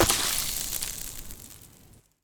poly_explosion_acid.wav